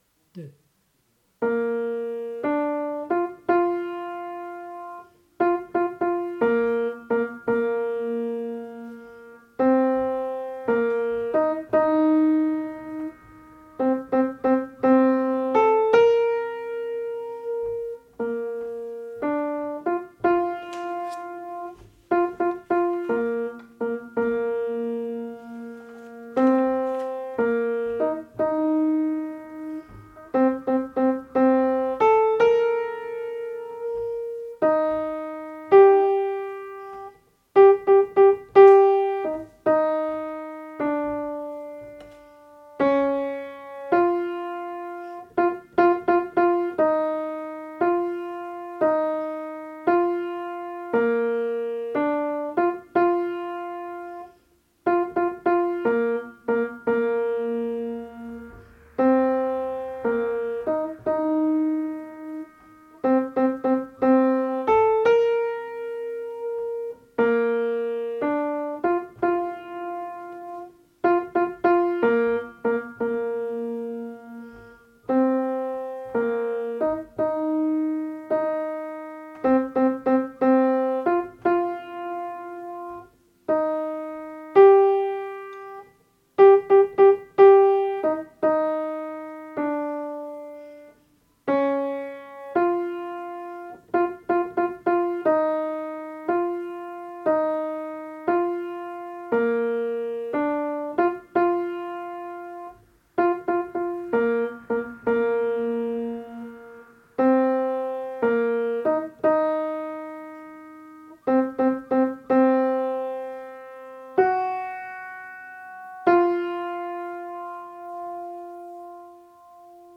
Chanson_de_Lara_altis.mp3